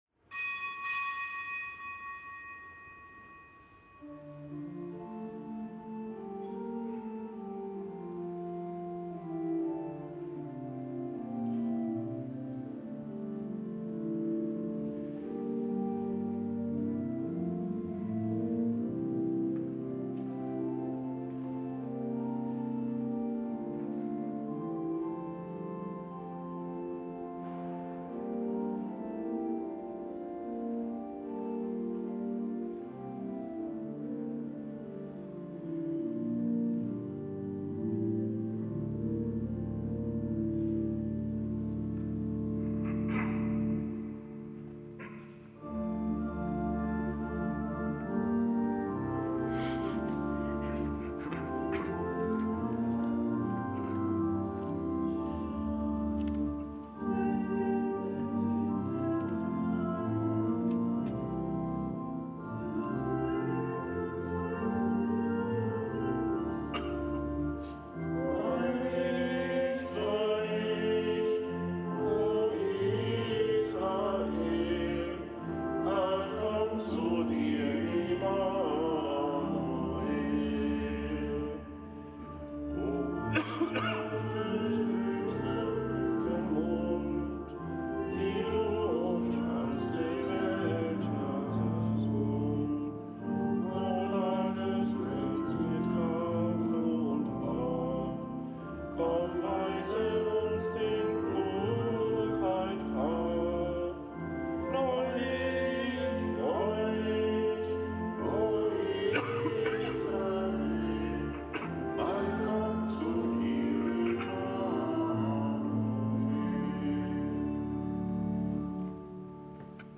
Kapitelsmesse aus dem Kölner Dom am Mittwoch der zweiten Adventswoche. Zelebrant: Weihbischof Dominikus Schwaderlapp.